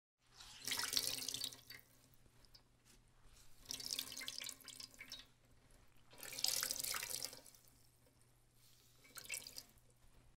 Звуки скатерти
7. Выкручивают мокрую ткань